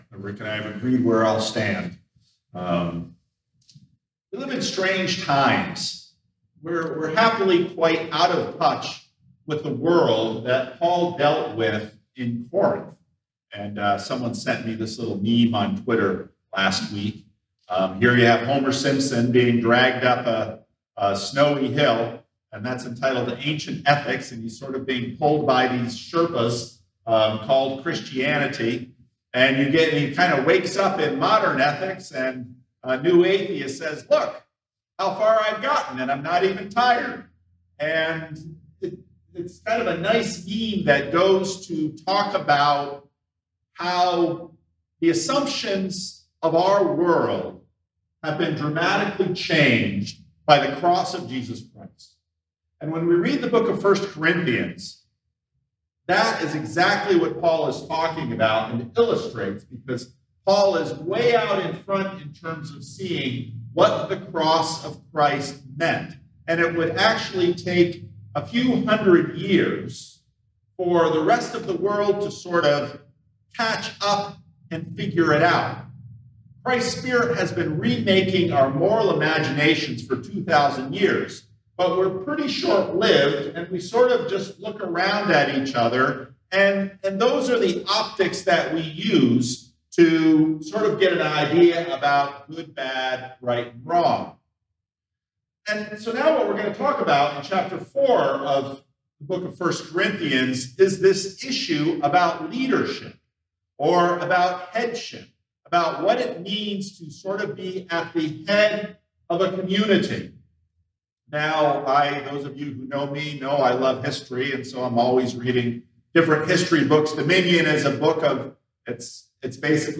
Sermons | Living Stones Christian Reformed Church